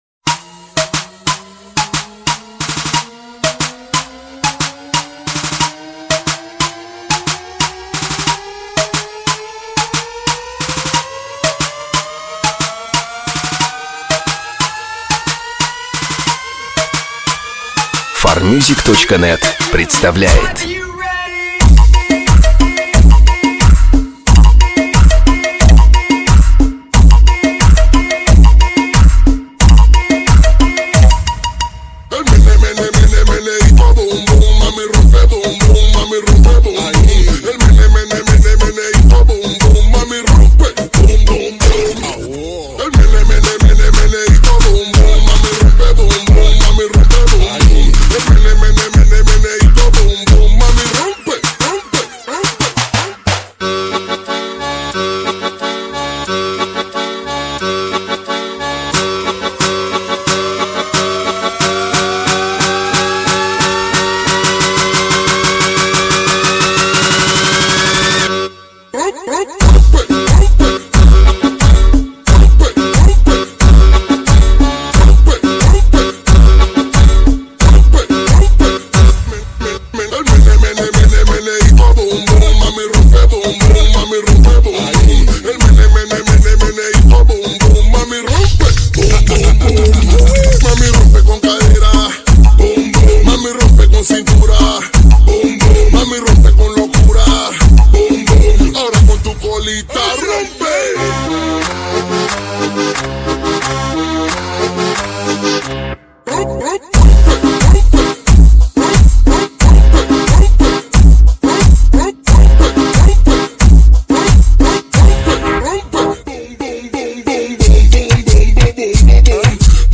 Клубные песни